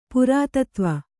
♪ purātatva